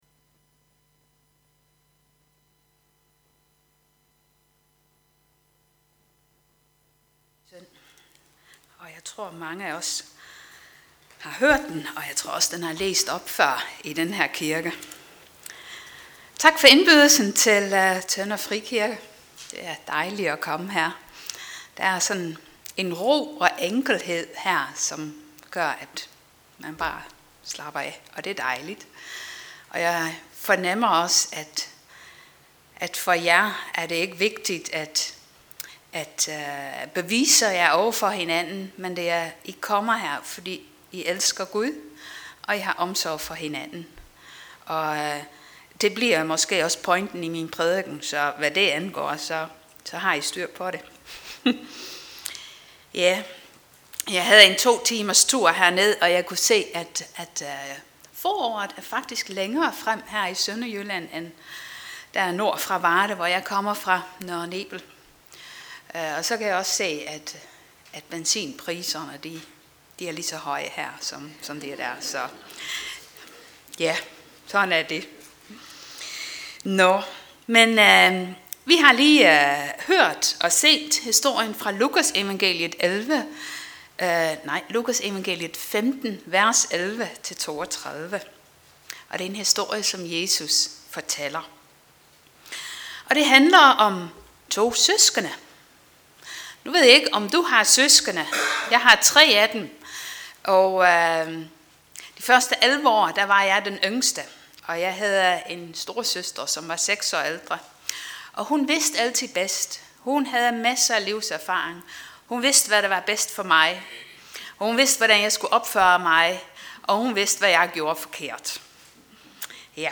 Passage: Lukas 15:11-32 Service Type: Gudstjeneste